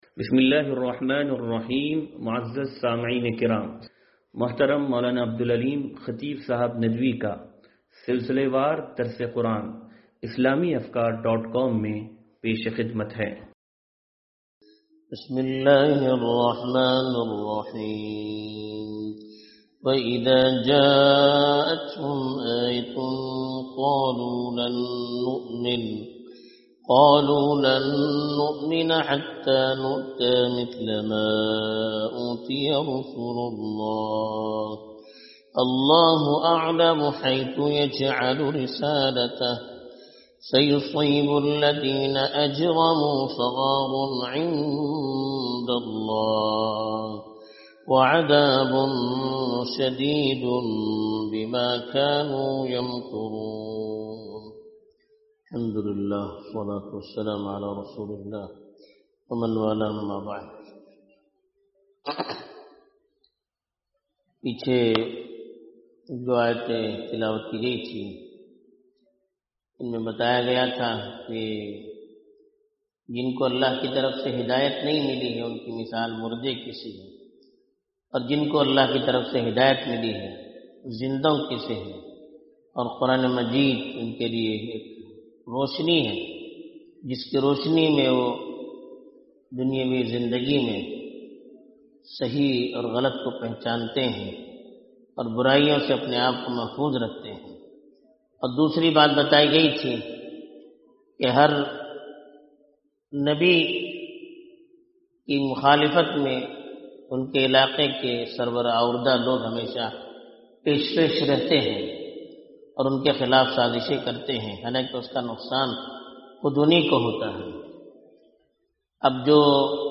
درس قرآن نمبر 0566